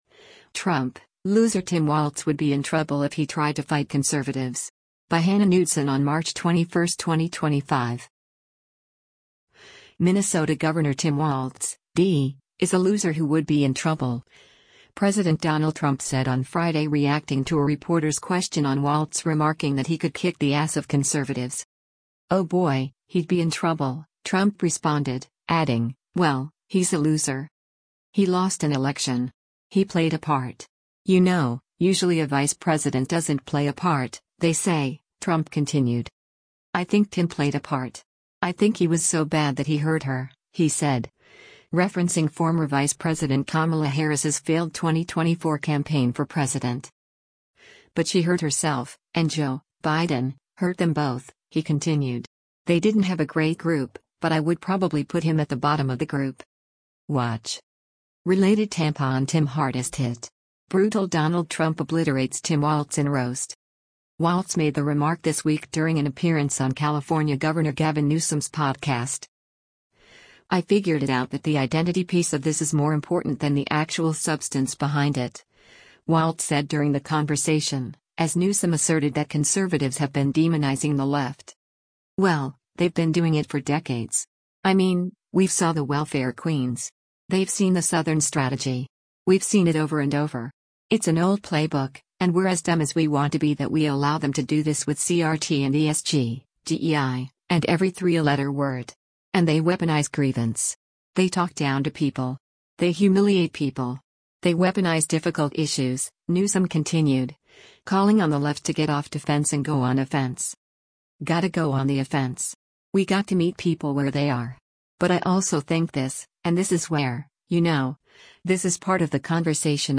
Minnesota Gov. Tim Walz (D) is a “loser” who would be in trouble, President Donald Trump said on Friday reacting to a reporter’s question on Walz remarking that he could kick the “ass” of conservatives.